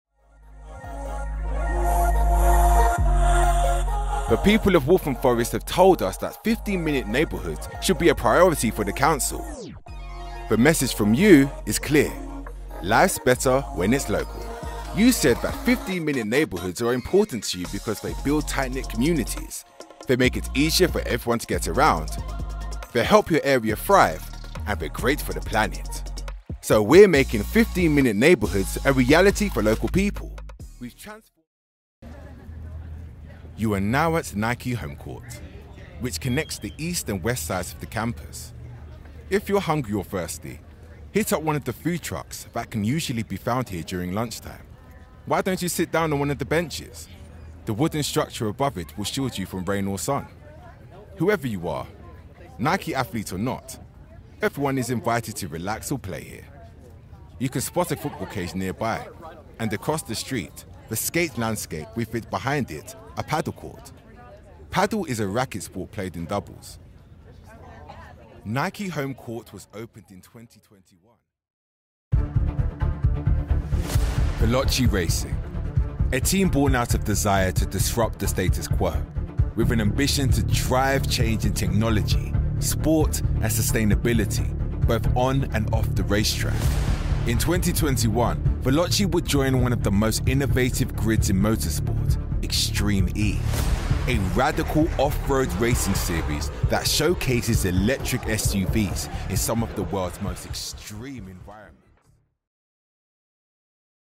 Male
British English
Authoritative, Cool, Deep, Engaging, Smooth, Streetwise, Warm
London (native), Neutral British
gaming.mp3
Microphone: aston origin